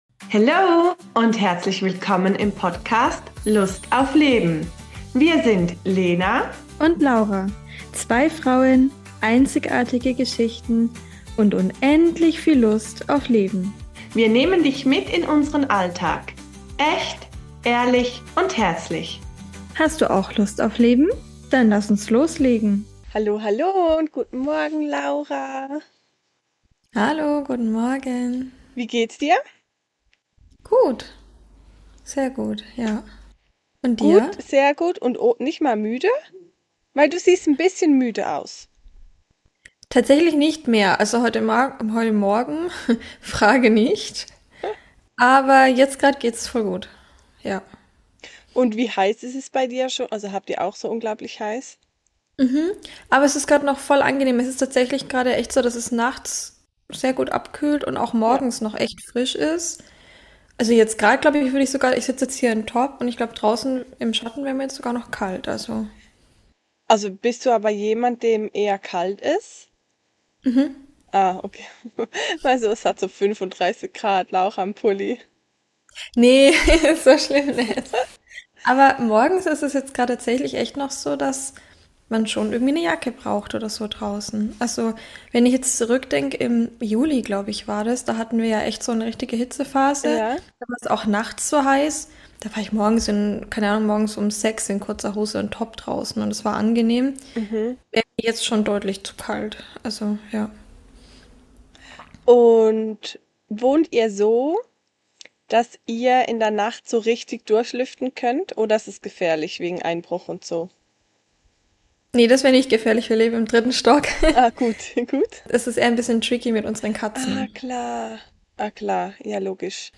Zwischen Katzen auf der Terrasse, Kinderlärm aus der Schule nebenan und verrückten Essenskombis haben wir es trotzdem geschafft, über die großen Themen des Lebens zu sprechen.